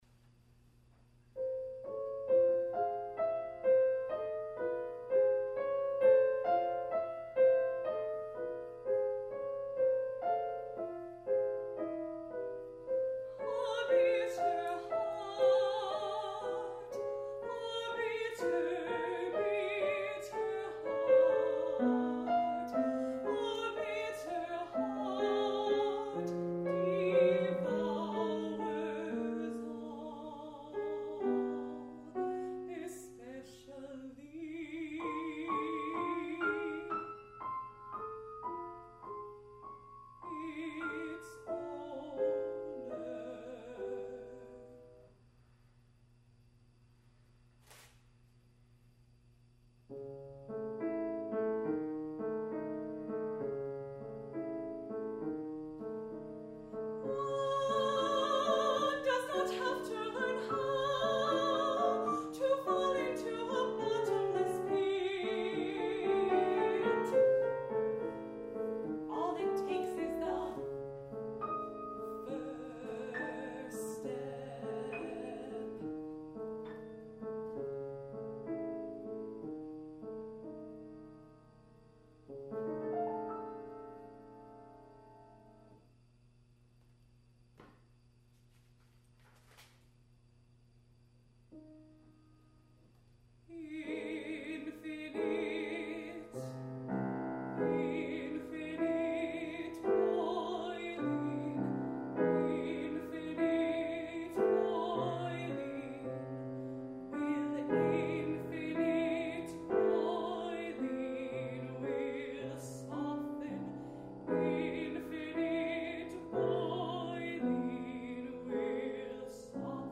Five Short African Songs for Alto and Piano